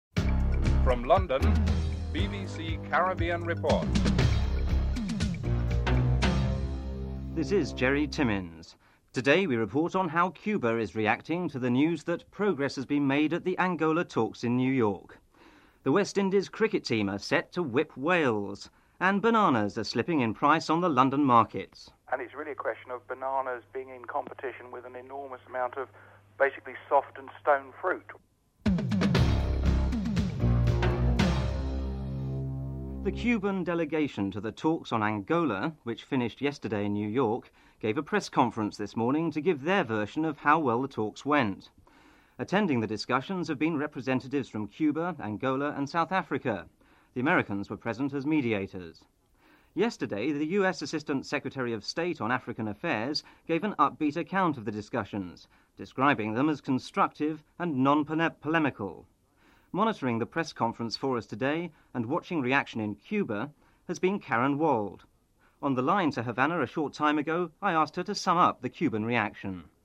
2. At a press conference, members of the Cuban delegation to the Angola talks in New York, give their version of how well the talks went.